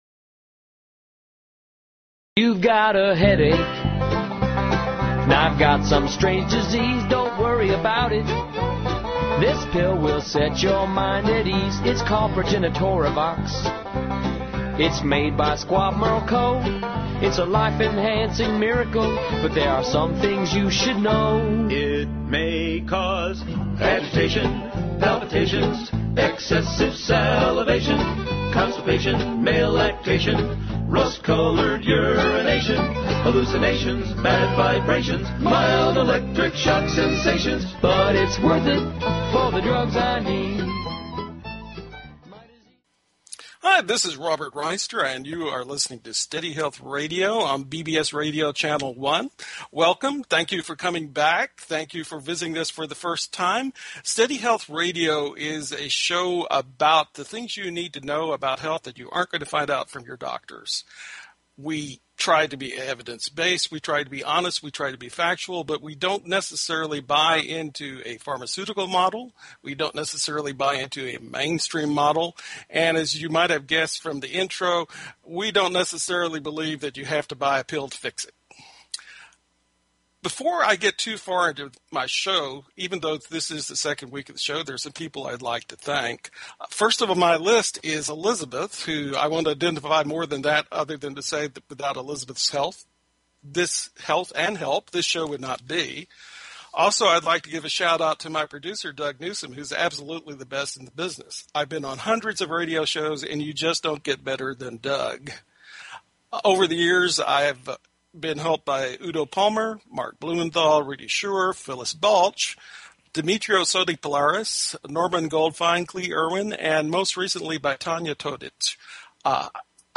Talk Show Episode, Audio Podcast, Steady_Health_Radio and Courtesy of BBS Radio on , show guests , about , categorized as